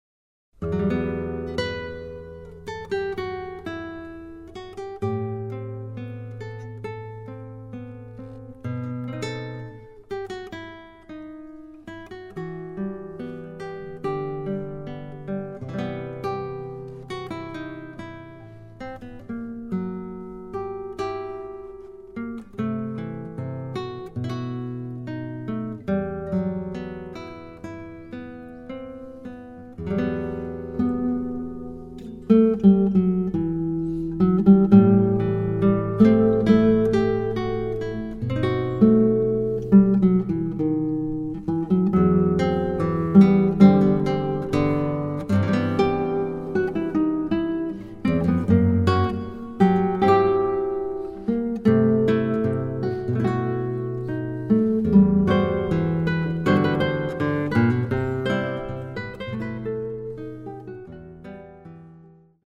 DÚO DE GUITARRAS